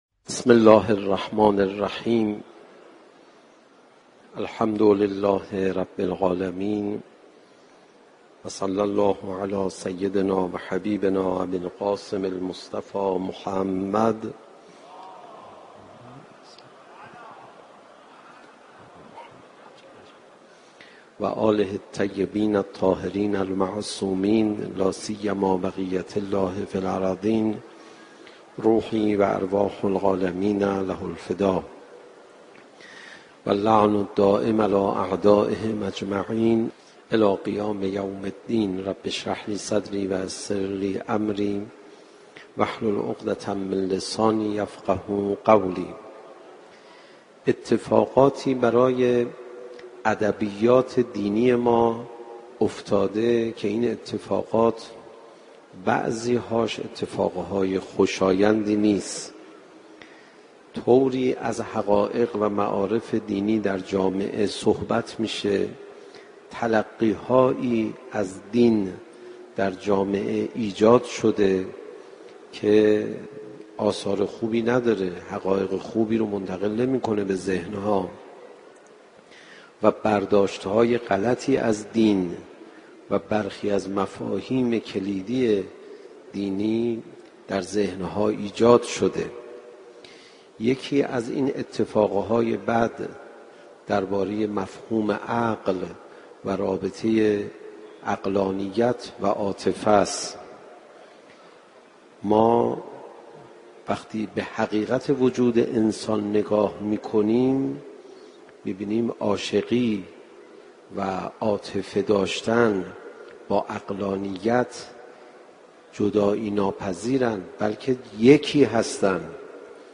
سخنرانی حجت الاسلام علیرضا پناهیان با موضوع یگانگی عشق و عقل - 2 بخش
صوت سخنرانی مذهبی و اخلاقی